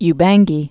(y-bănggē, -bäng-)